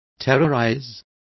Complete with pronunciation of the translation of terrorize.